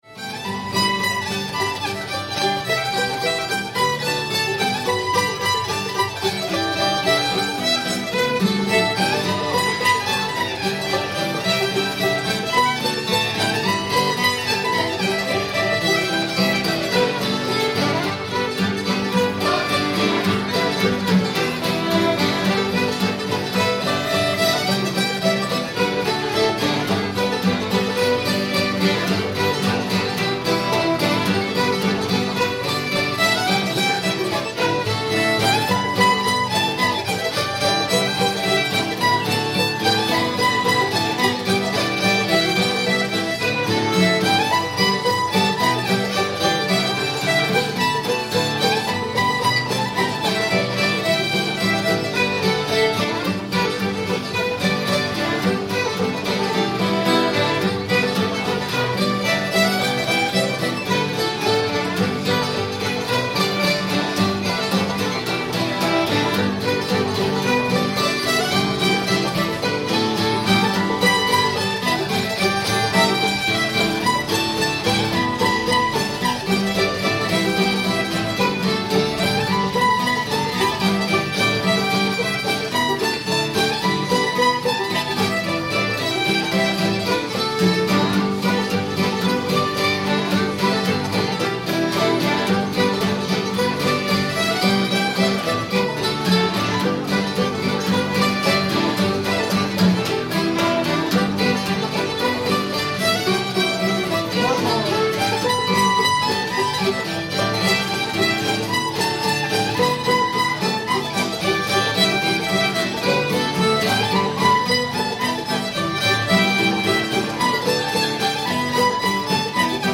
springfield girl [G]